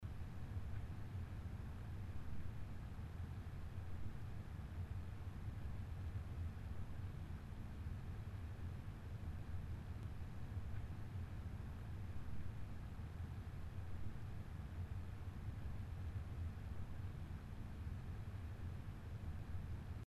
Ilyenkor a két megoldás közötti zajkülönbség nem eget verő, de az ASUS megoldása halkabban, némi szélzajjal, de a referenciakártyára jellemző idegesítő csapágyhang nélkül üzemel.
hangfájlban is megörökítettünk (az első 10 másodperc alapjárat, míg a második terhelés).
asus_gtx670_mini_fan.mp3